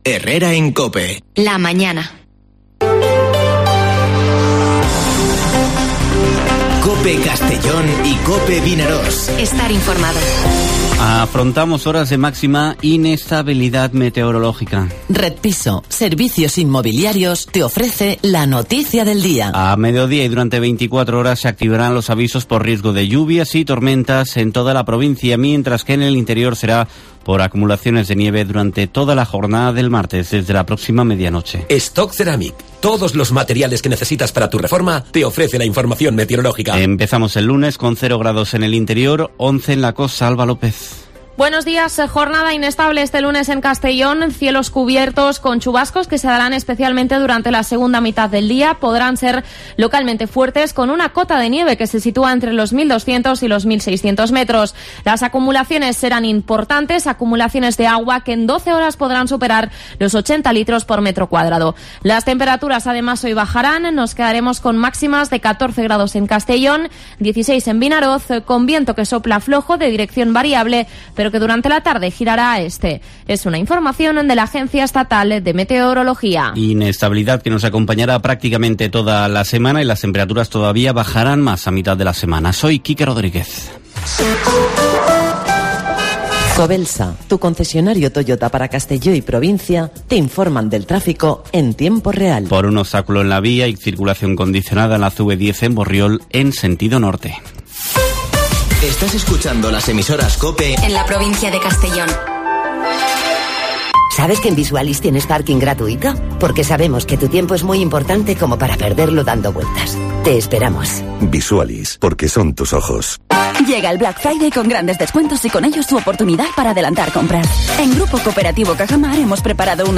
Informativo Herrera en COPE en la provincia de Castellón (22/11/2021)